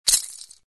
Звуки маракасов
Звук маракас потрясли один раз